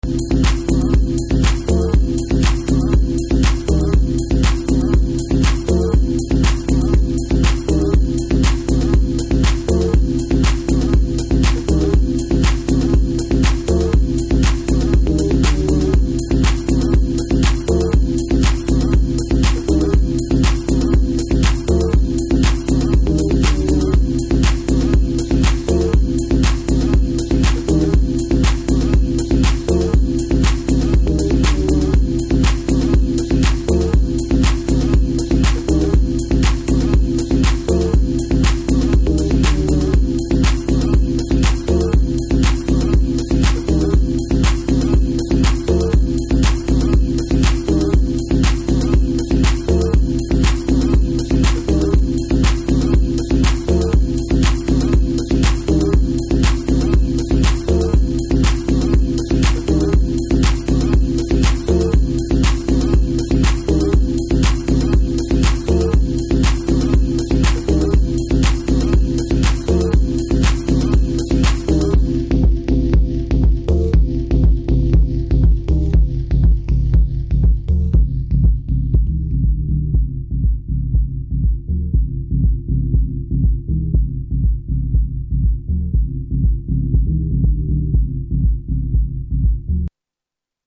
electronic music underground